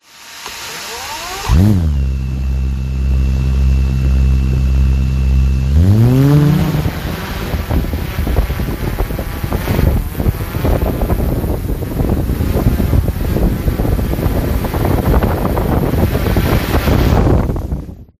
Sonido de un dron volando